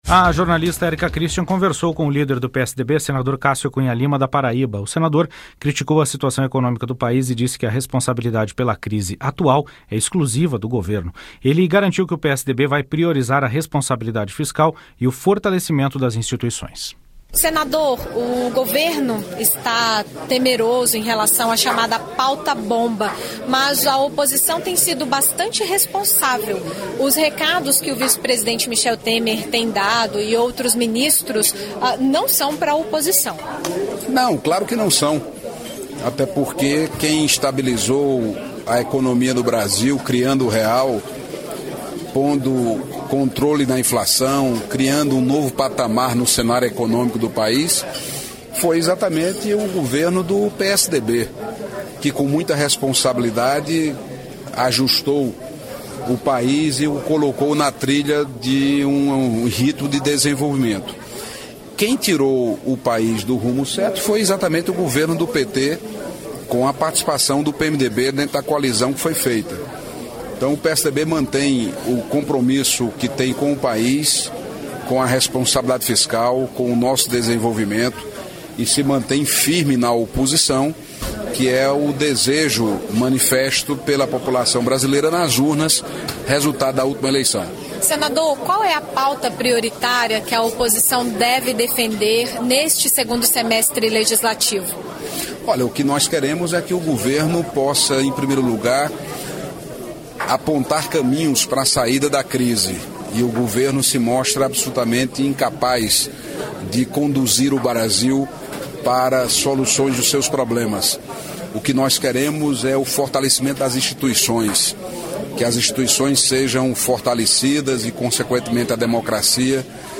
Senado em Revista: Entrevistas - 07/08/2015